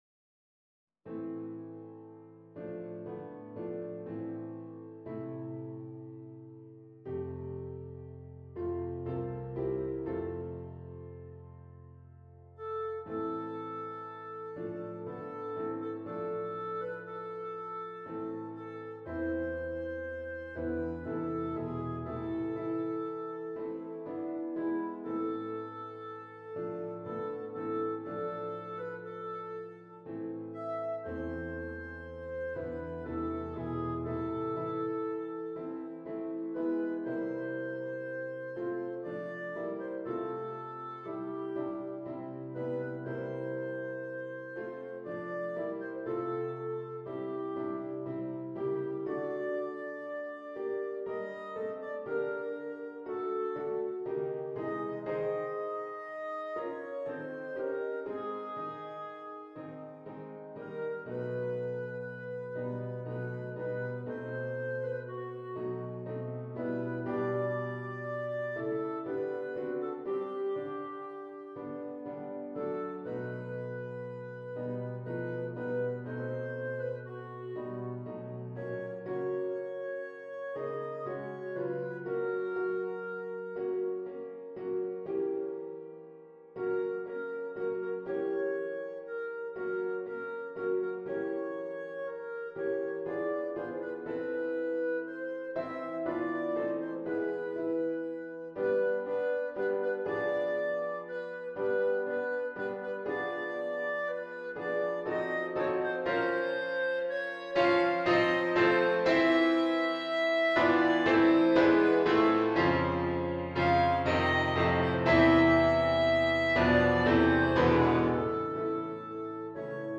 full performance, accompaniment, and Bb clarinet